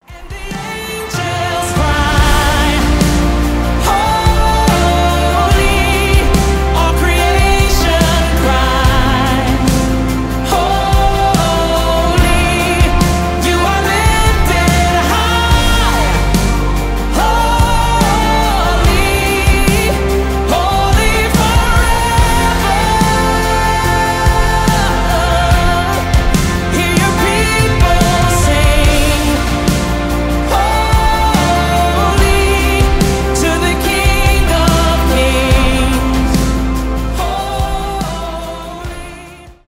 госпел , душевные , поп